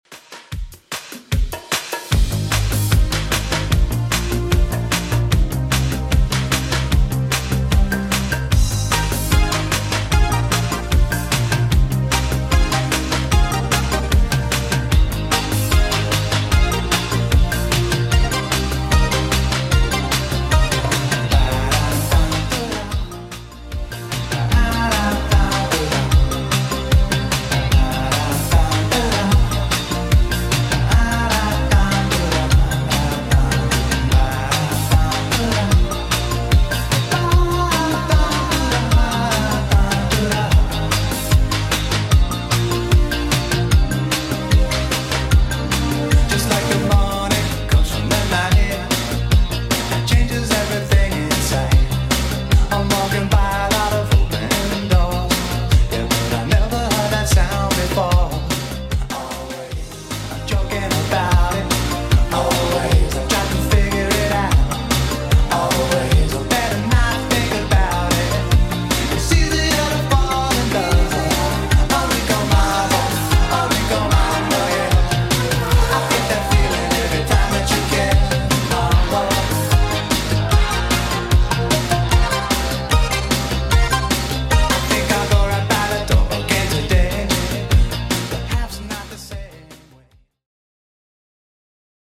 Genre: 80's Version: Clean BPM: 108